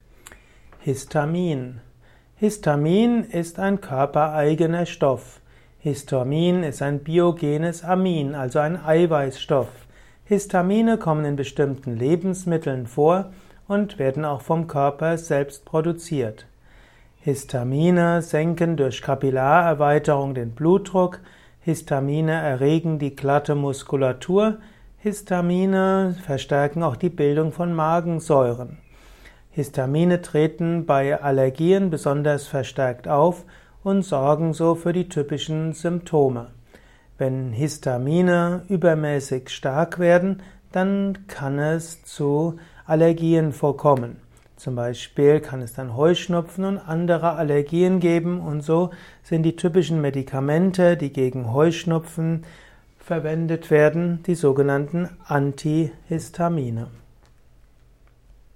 Simple und komplexe Infos zum Thema Histamin in diesem Kurzvortrag